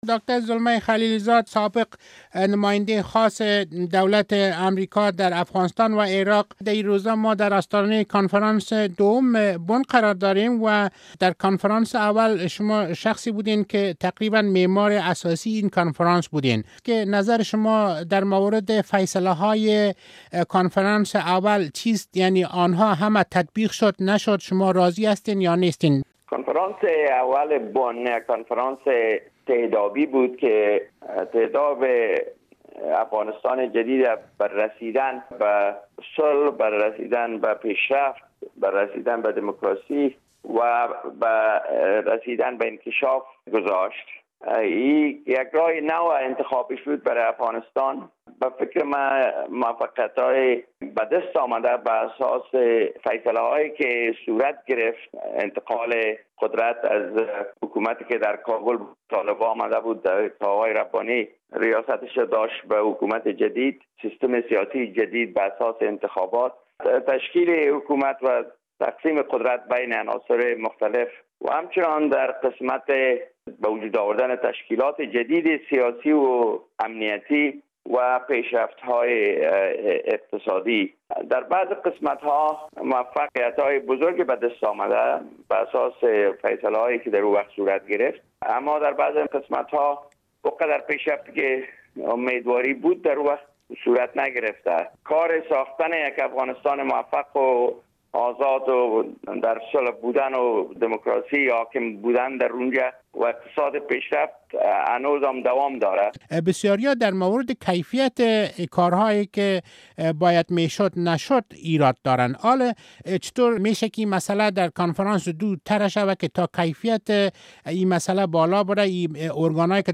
مصاحبه با زلمی خلیل زاد در مورد کنفرانس دوم بن